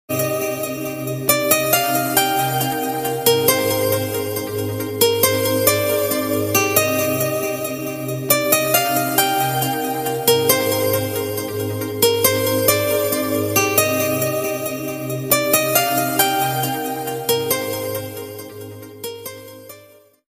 آهنگ زنگ گیتار دلنواز احساسی